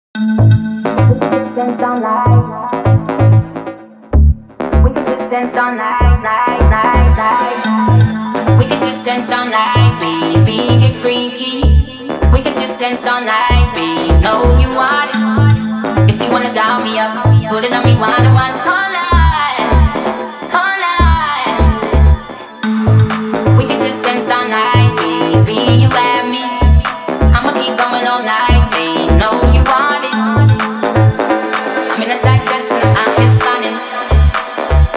House Dance
Жанр: Танцевальные / Хаус